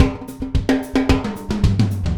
Звуки латино
Latin Drum Fill Groove 3